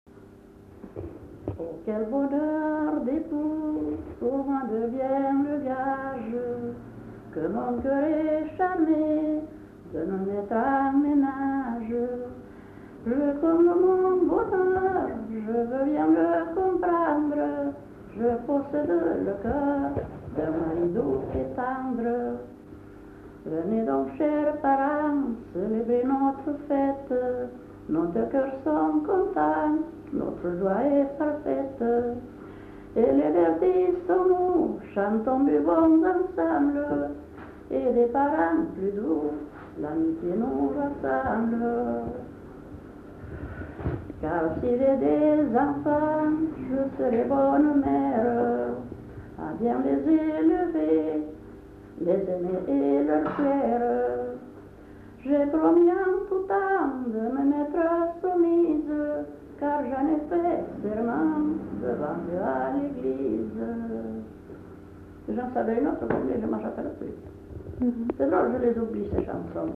[Brocas. Groupe folklorique] (interprète)
Lieu : [sans lieu] ; Landes
Genre : chant
Effectif : 1
Type de voix : voix de femme
Production du son : chanté